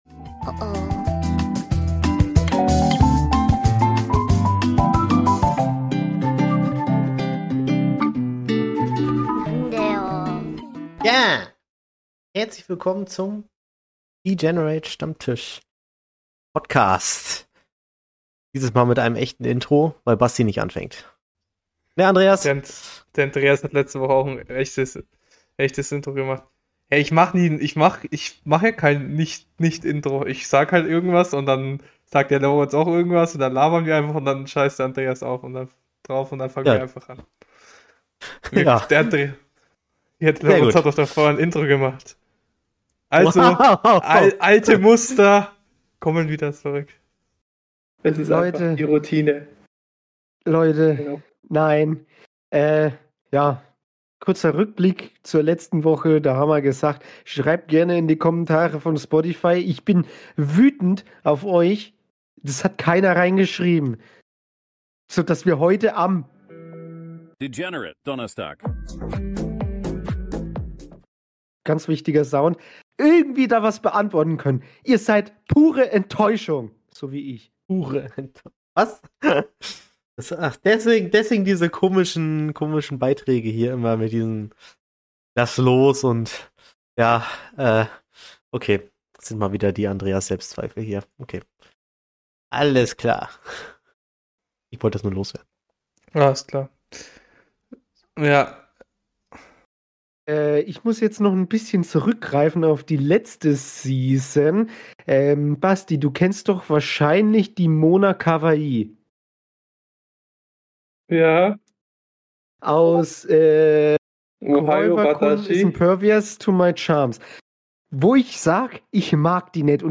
Hört rein für eine Episode mit etwas schlechterer Tonqualität, aber dafür viel Chaos und eine ordentliche Portion Anime-Talk!